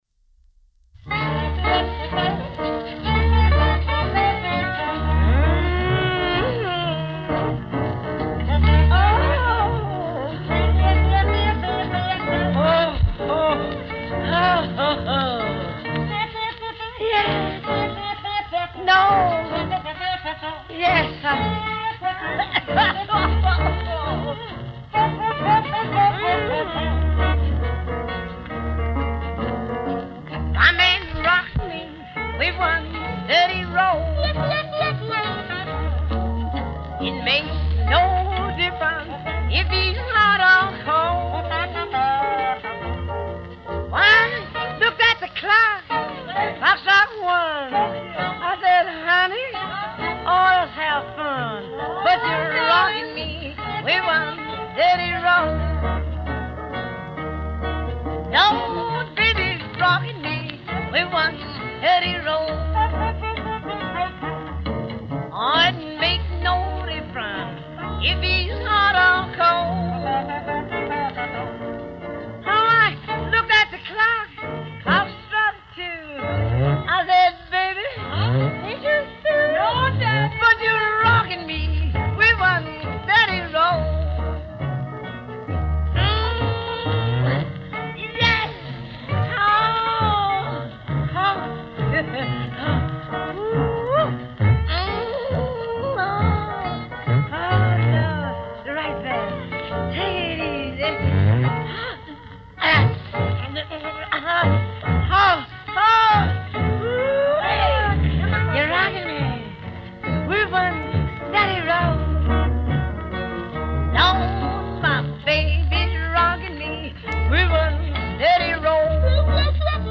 Blues Artists